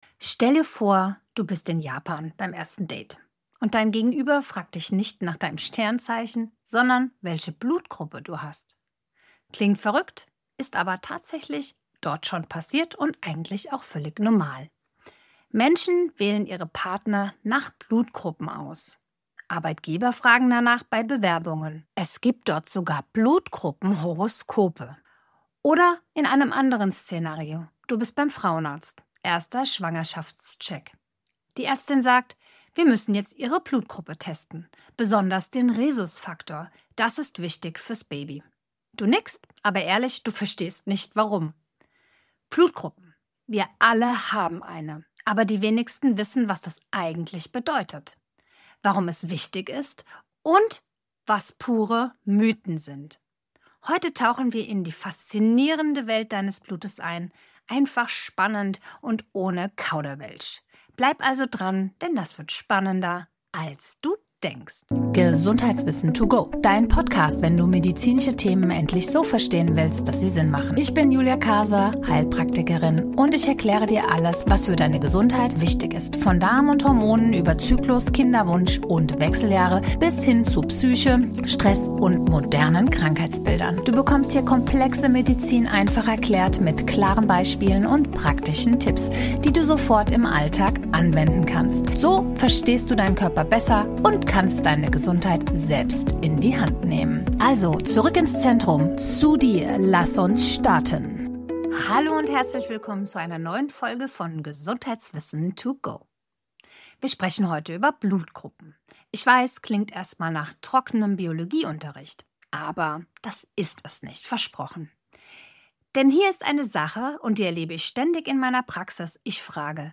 Warm erzählt.